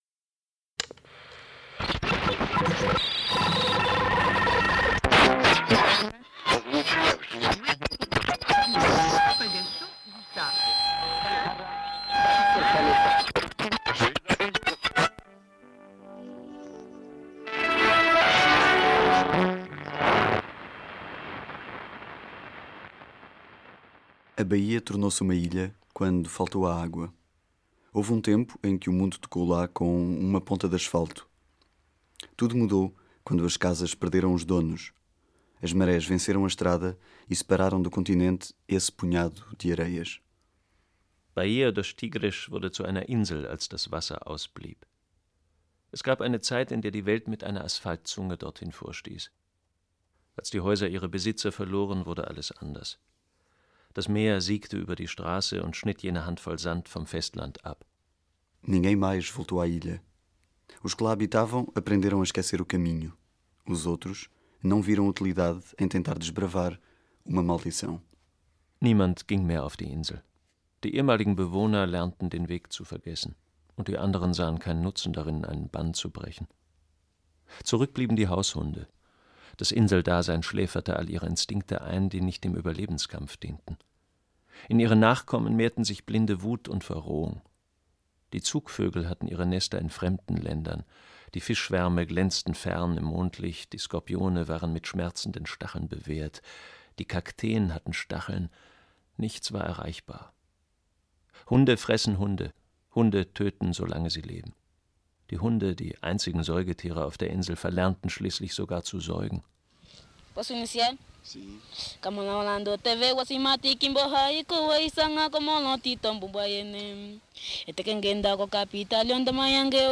Das dokumentarische Tonmaterial tritt mit dem literarischen Text in Dialog und verdichtet sich zu einer akustischen Landschaft. Das Heulen eines Lastwagens im Treibsand, der Rap eines Straßenjungen aus dem zerbombten Kuíto.